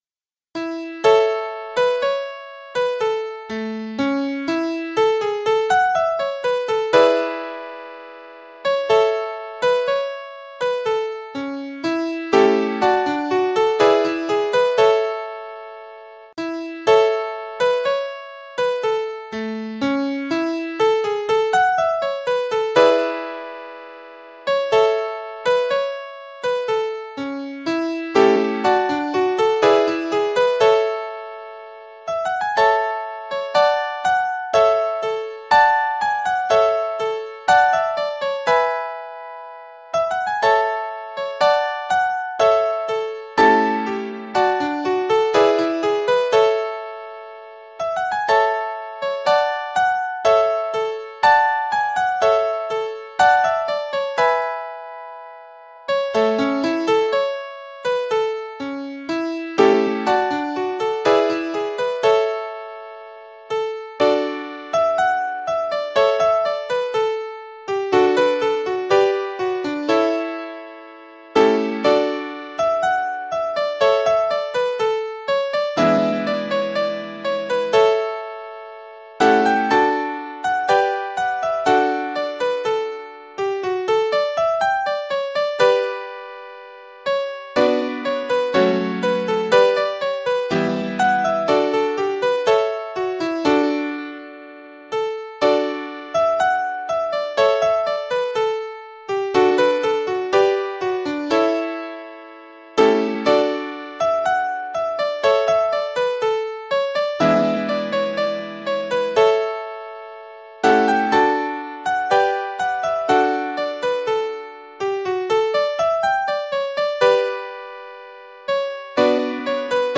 The score Listen to the tunes Simulated piano at 60 beats per minute (MP3) Listen to the tunes Simulated piano at 56 beats per minute (MP3) Listen to the tunes Simulated piano at 50 beats per minute (MP3)
StJohnsRiver60bpm.mp3